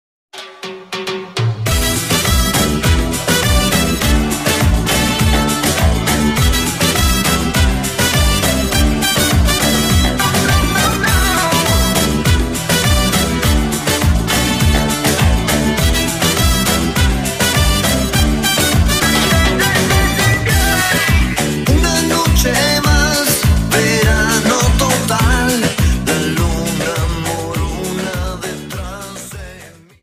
Dance: Samba 50